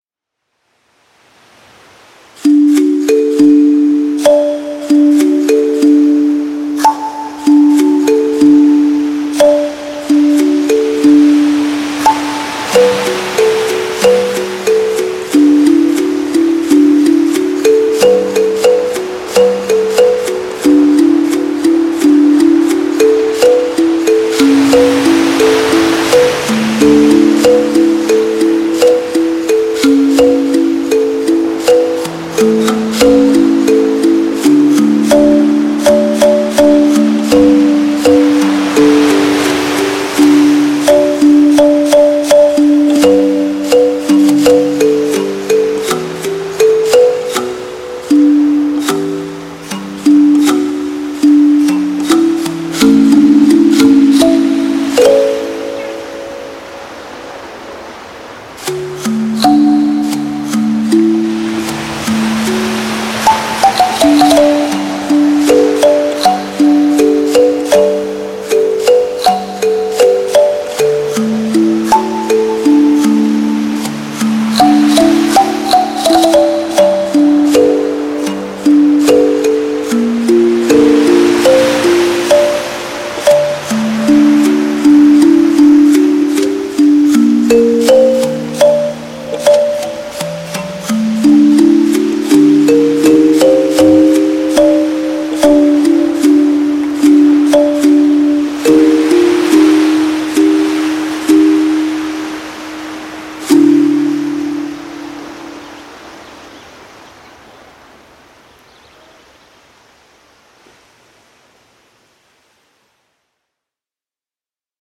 • Жанр: Детские песни
🎶 Детские песни / Музыка детям 🎵 / Музыка для новорожденных